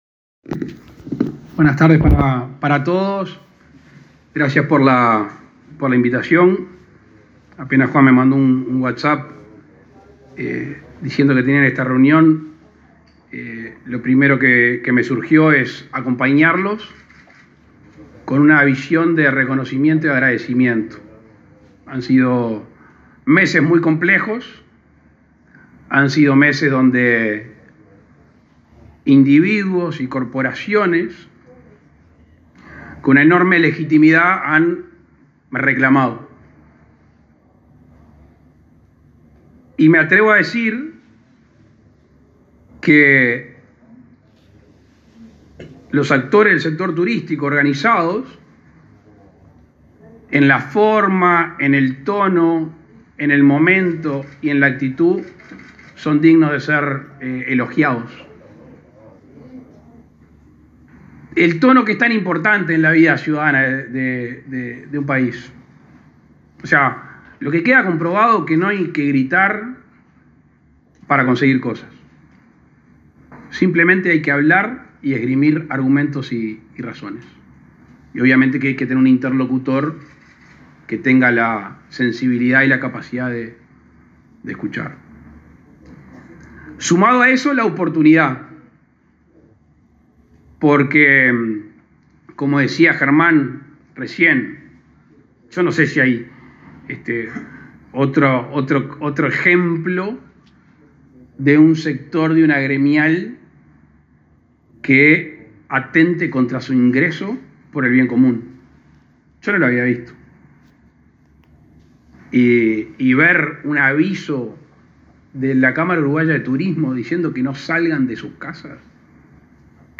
Presidente Lacalle Pou participó en la presentación de la estrategia promocional de turismo interno